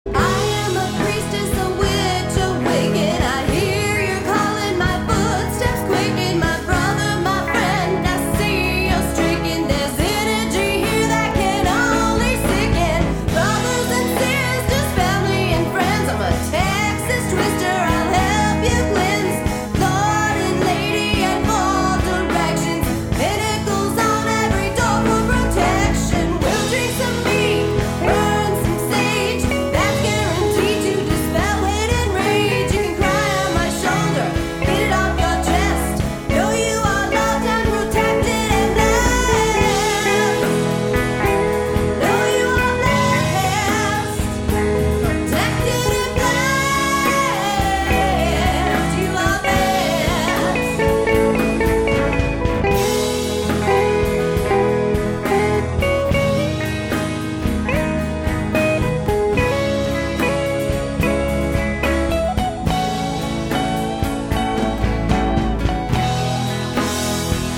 a Pagan rock opera